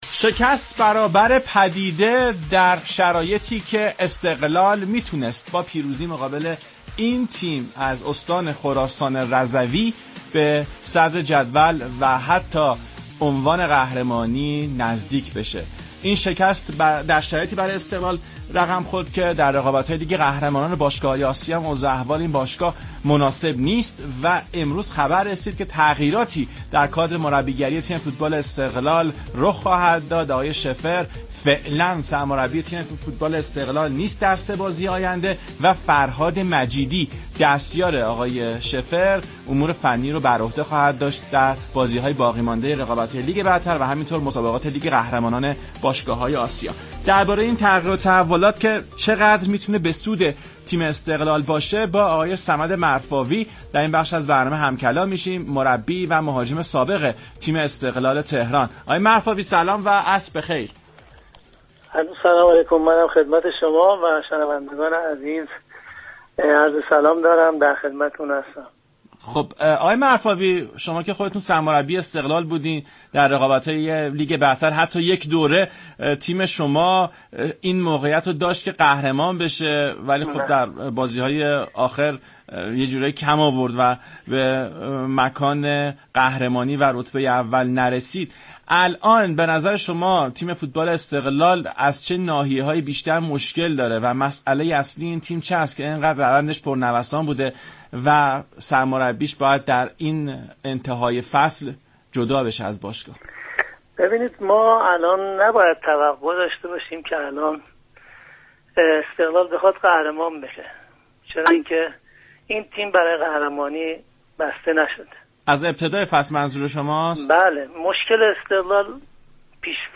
صمد مرفاوی بازیكن و مربی سابق تیم استقلال در برنامه ورزش ایران رادیو ایران گفت : مشكل استقلال پیش فصل است